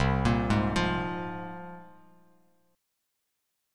C7sus4#5 chord